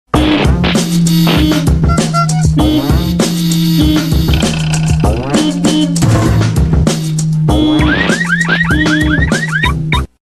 Cartoon Traffic Jam
cartoon-traffic-jam.mp3